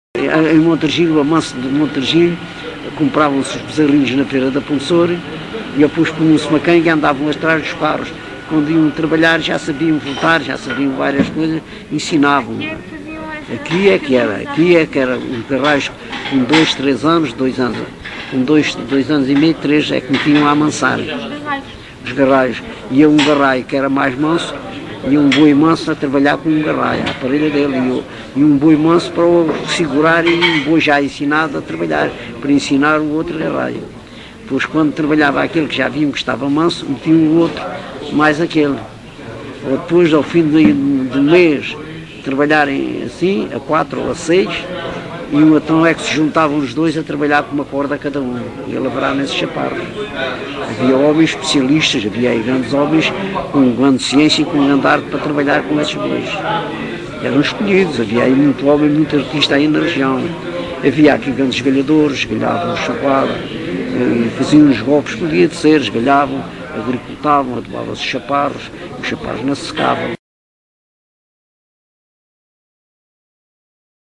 LocalidadeCouço (Coruche, Santarém)